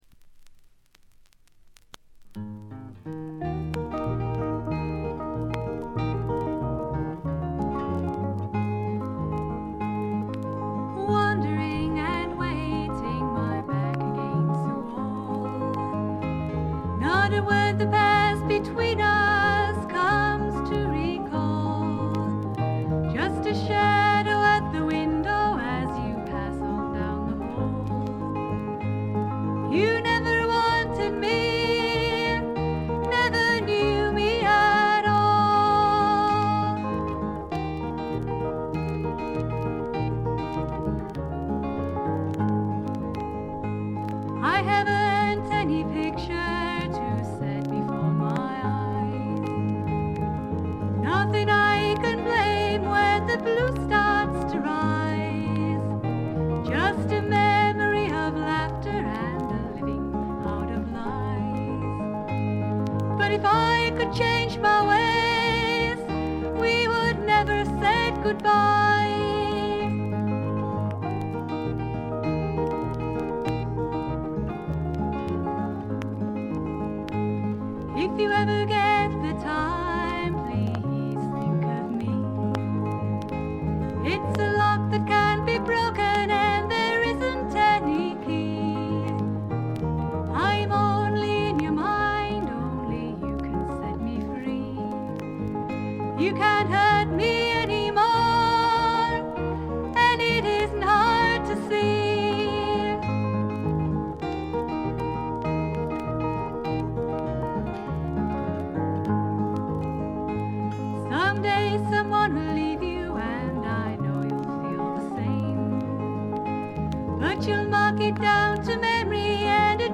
常時大きめのバックグラウンドノイズ。チリプチ、プツ音等も多め大きめ。
清楚でかわいらしくしっとりとした、実に魅力ある声の持ち主で、みんなこの透きとおるヴォイスにやられてしまうんですね。
試聴曲は現品からの取り込み音源です。
Guitar, Electric Guitar
Keyboard